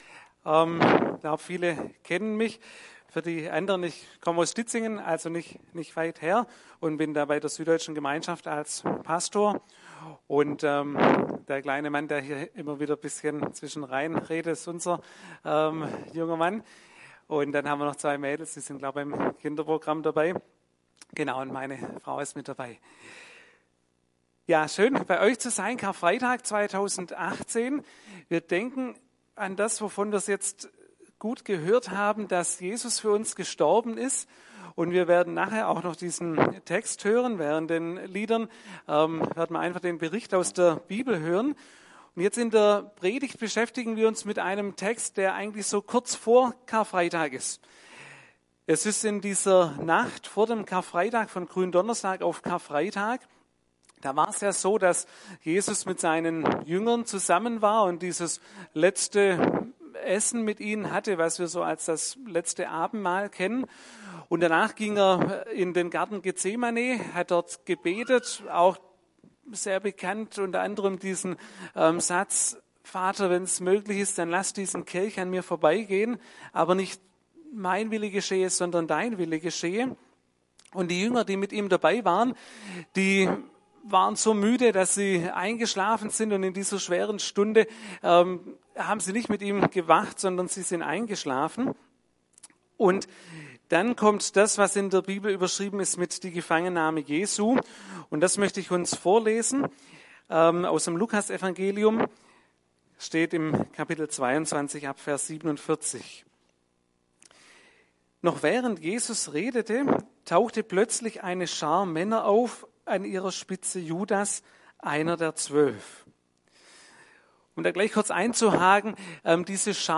Predigt vom 8. April 2018 › ETG-Ludwigsburg